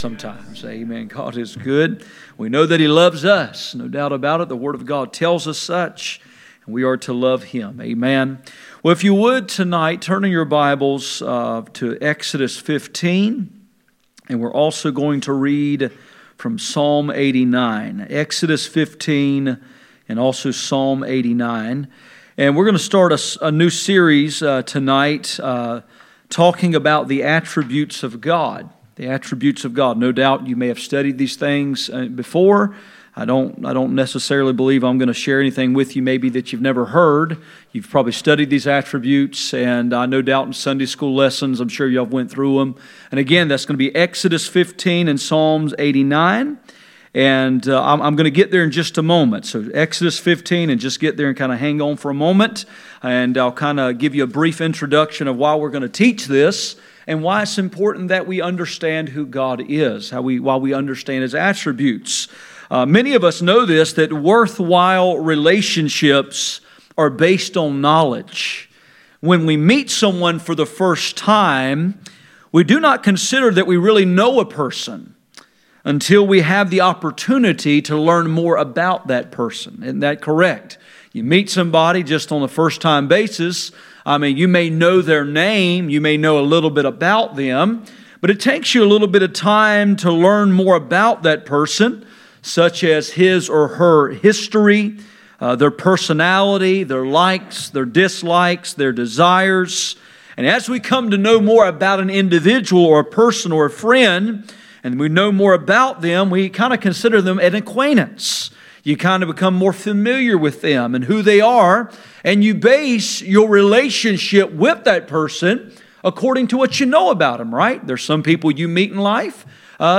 Exodus 15:11-13 Service Type: Midweek Meeting %todo_render% « Why the preacher must preach the word of God